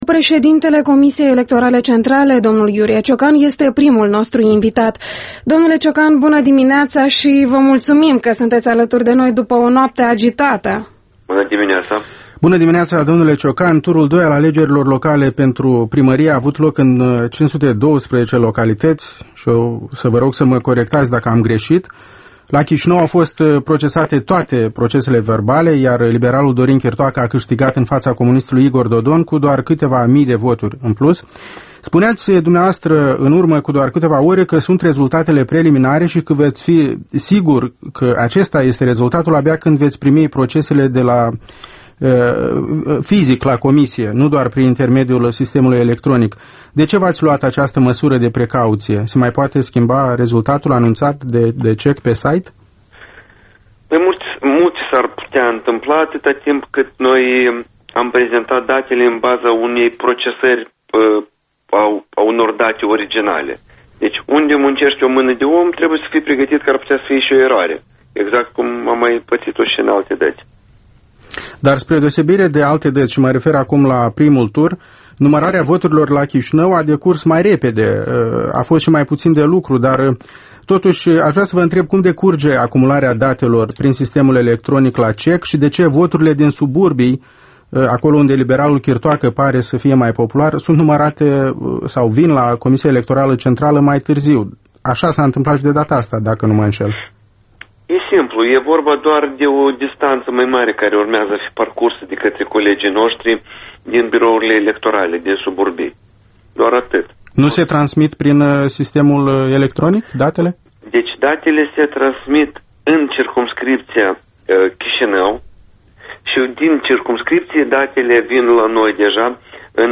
Interviul matinal EL: în direct cu Iurie Ciocan președintele CEC